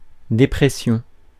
Ääntäminen
France: IPA: [de.pʁɛ.sjɔ̃]